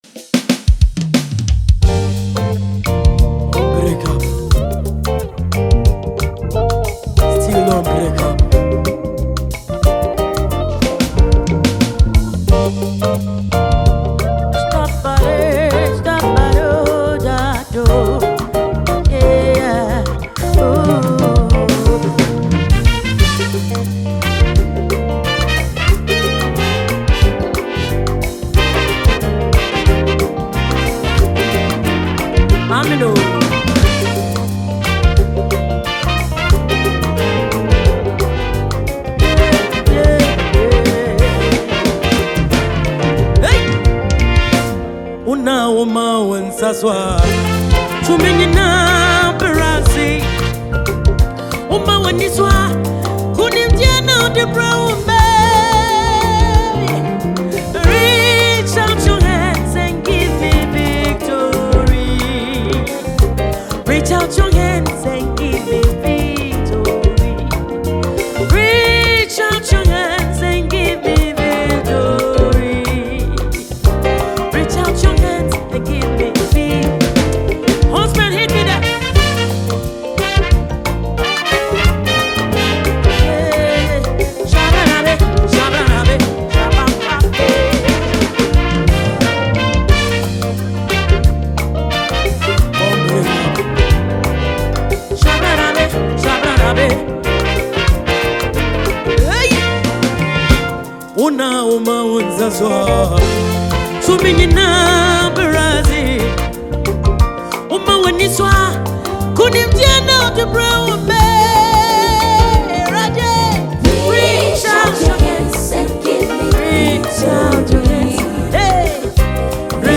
Ghanaian award-winning Gospel singer